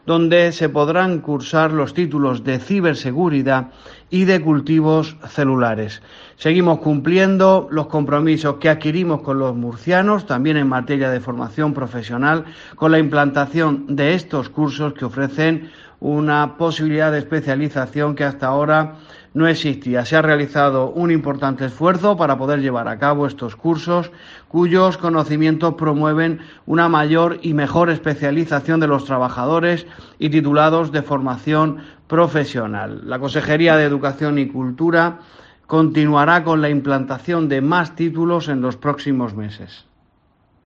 Declaraciones del director general de Evaluación Educativa y Formación Profesional, Sergio López.